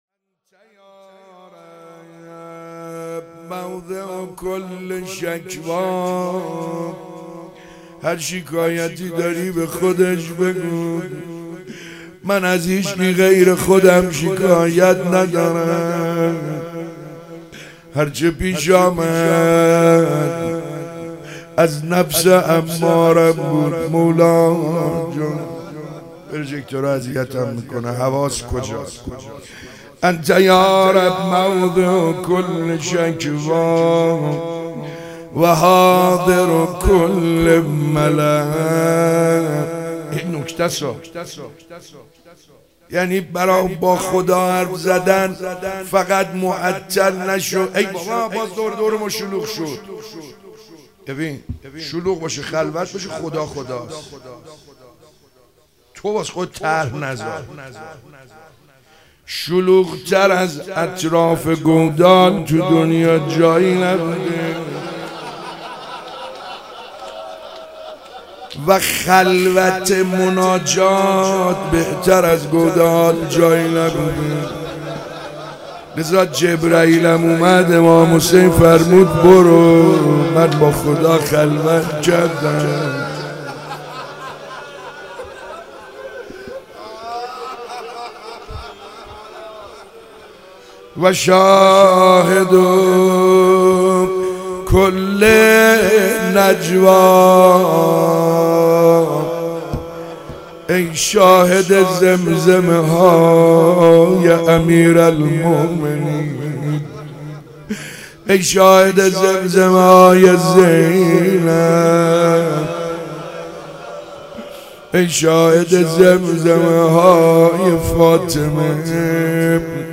شب 2 فاطمیه 95 - مناجات خوانی